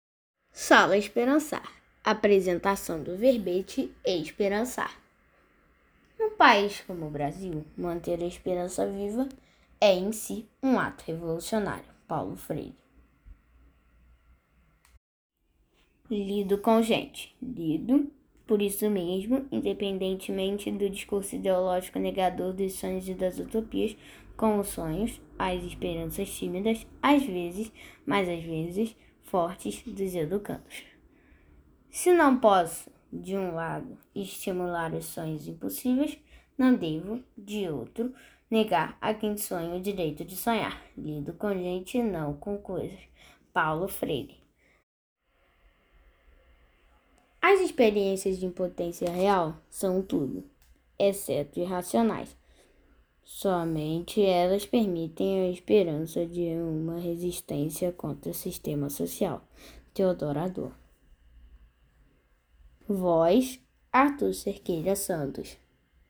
Verbete com voz humana